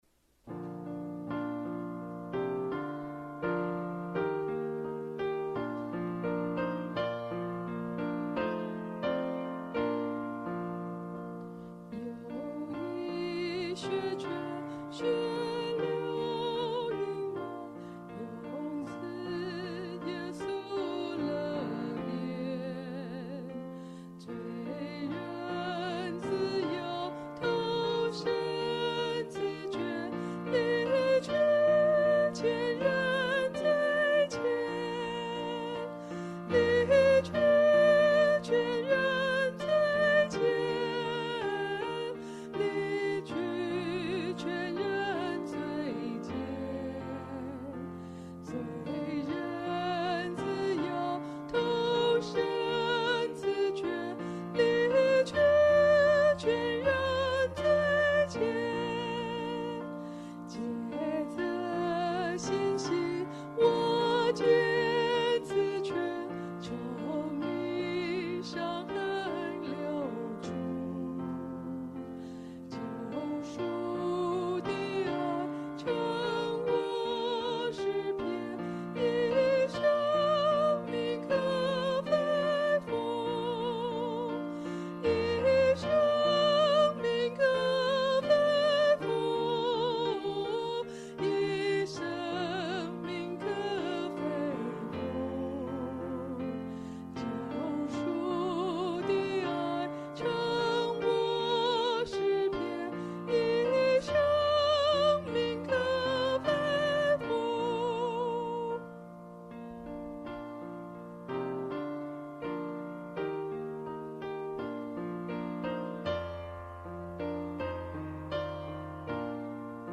赞美诗 | 有一血泉